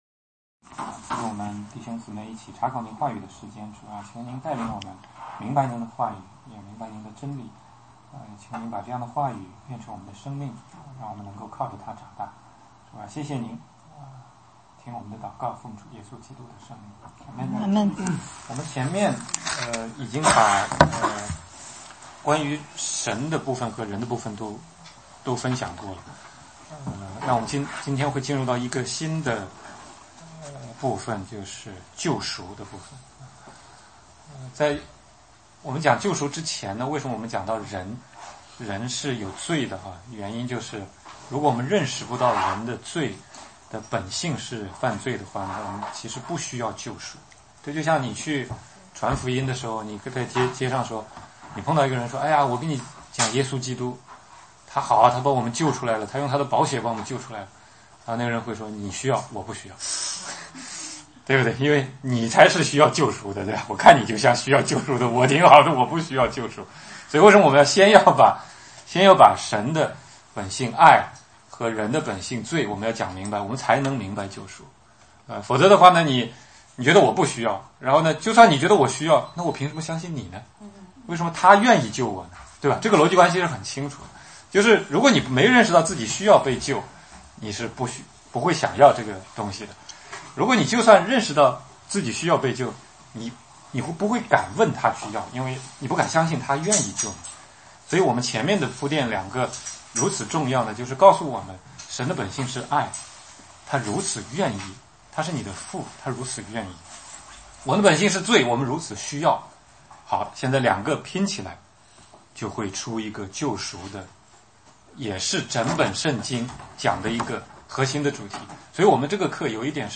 16街讲道录音 - 救赎 以赛亚书53